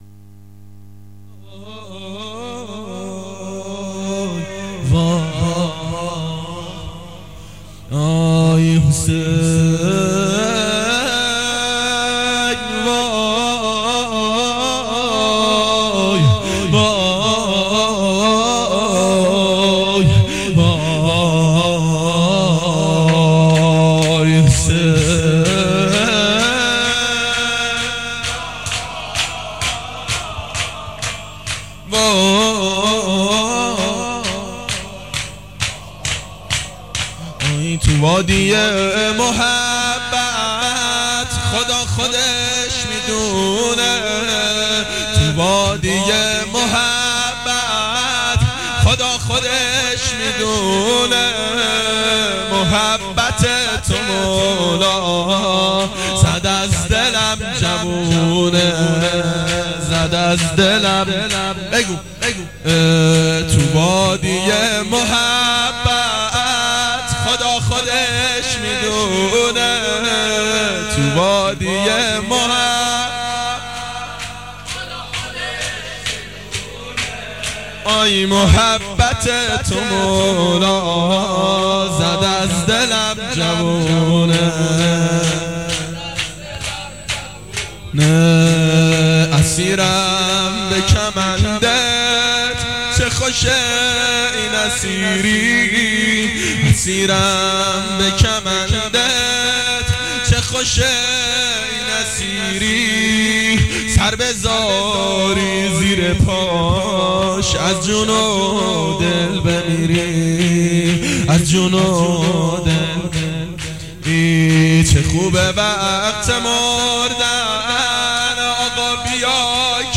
گلچین مداحی